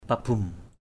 /pa-bum˨˩/ kabum kb~’ [Cam M] [A,59]